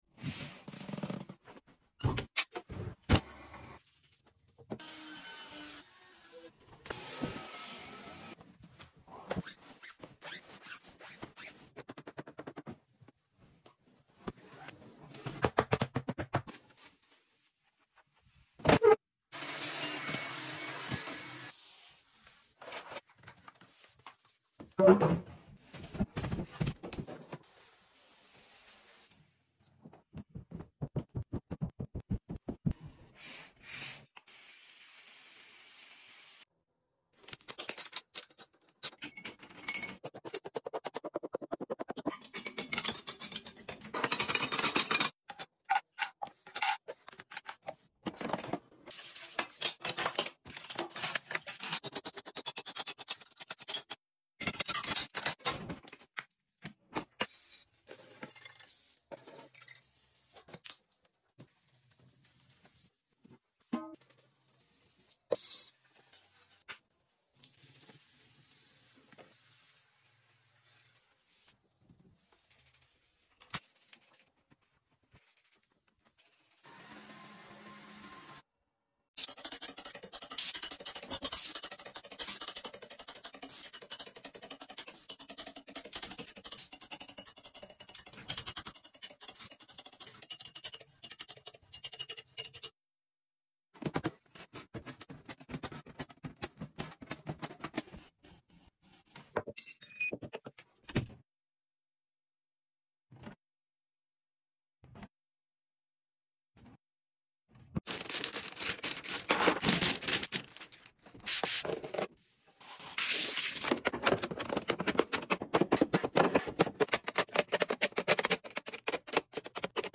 Cooking, cleaning, restocking and organizing sound effects free download
Cooking, cleaning, restocking and organizing asmr